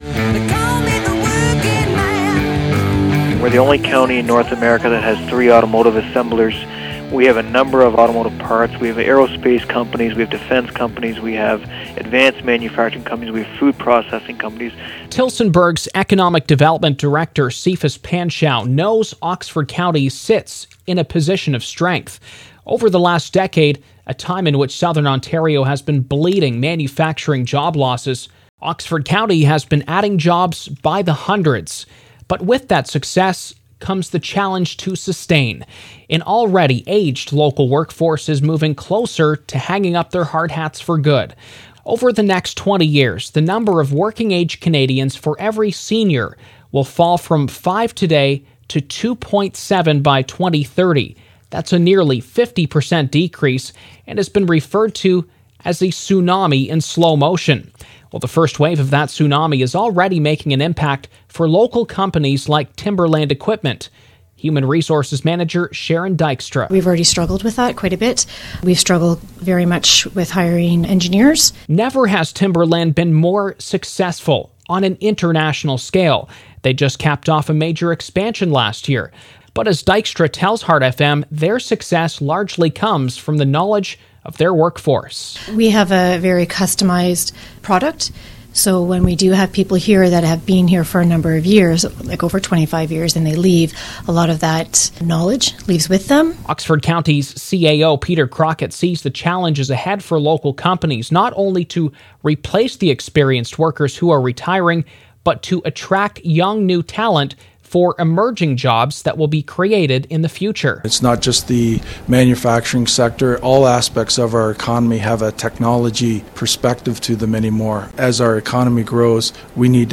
Inside Timerland Equipment Ltd.